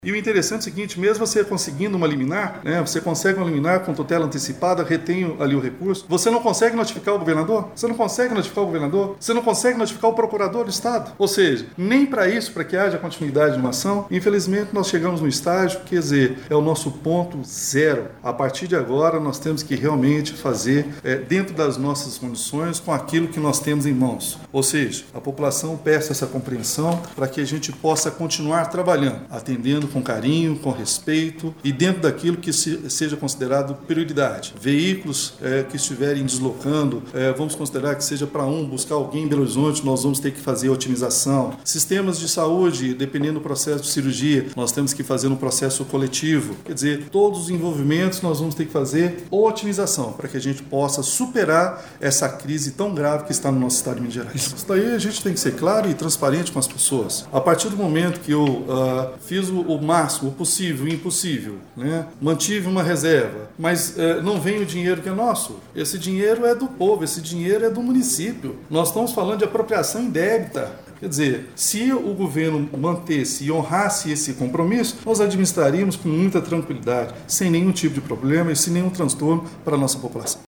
O prefeito Elias Diniz (PSD) convocou uma entrevista coletiva para informar às mudanças que serão implantadas nos próximos dias.